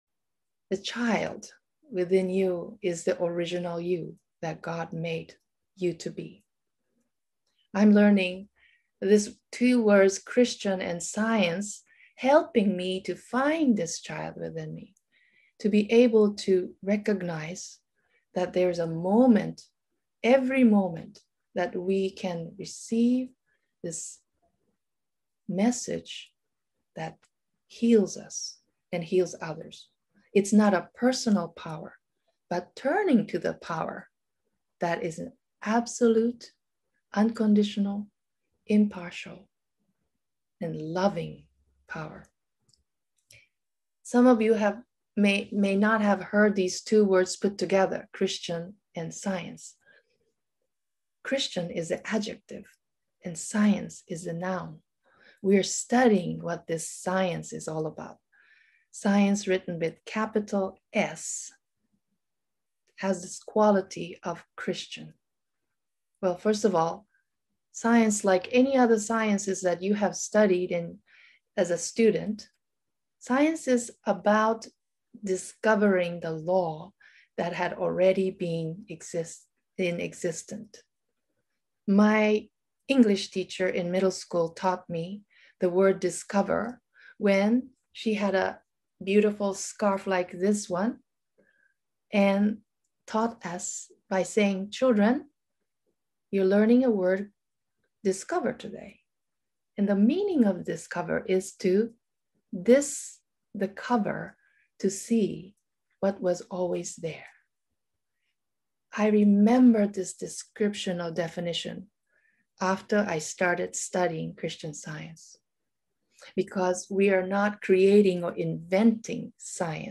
You can watch the video recording of this lecture on YouTube:
Note that, due to technical difficulties, the first 10 minutes of the lecture were not recorded successfully and are missing from the recording.